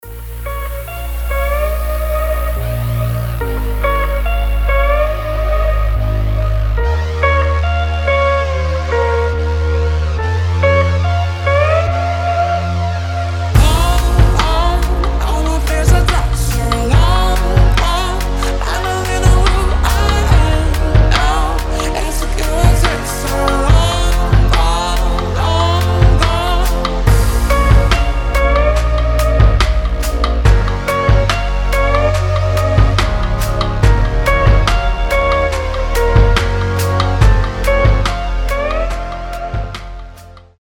• Качество: 320, Stereo
гитара
deep house
женский голос
медленные
RnB
Необычный дип хаус, как-то очень арэнбишно звучит)